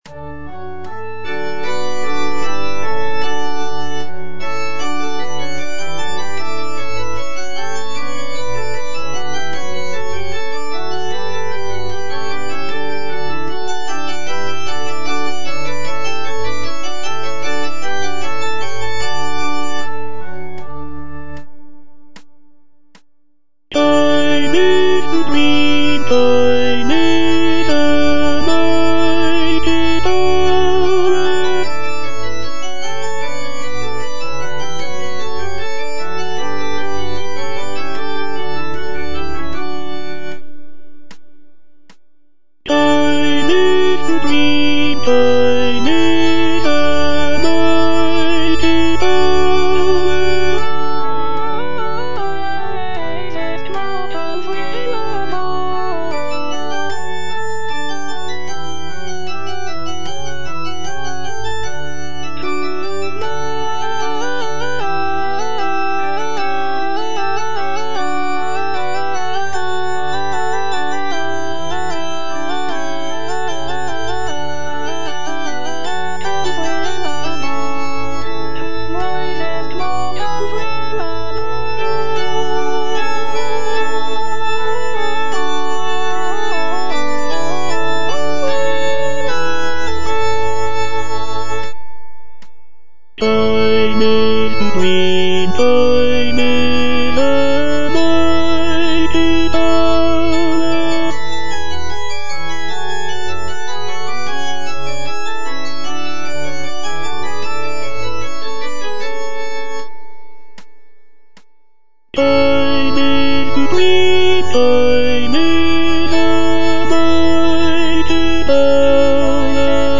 G.F. HÄNDEL - THE TRIUMPH OF TIME AND TRUTH HWV71 2. Time is supreme - Tenor (Voice with metronome) Ads stop: auto-stop